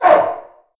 c_goril_hit1.wav